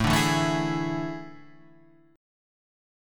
AM7sus4 chord {5 5 2 2 5 4} chord